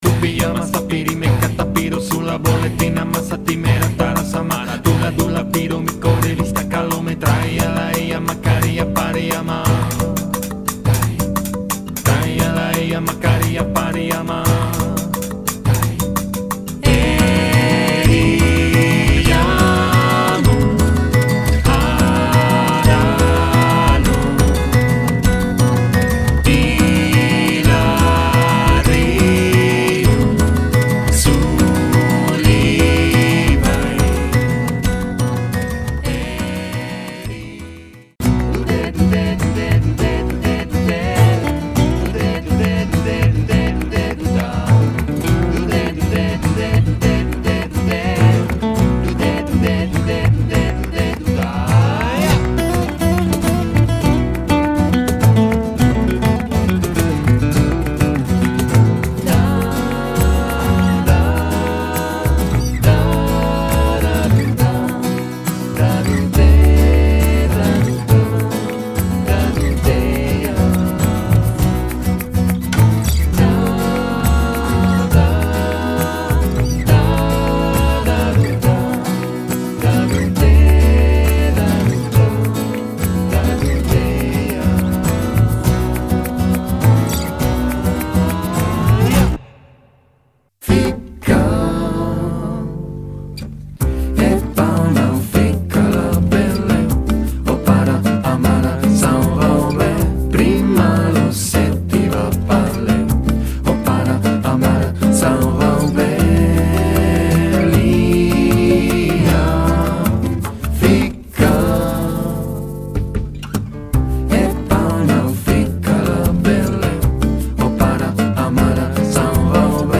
Cuarteto
guitarra, voz y percusión.
contrabajo.
flauta, percusión y voz.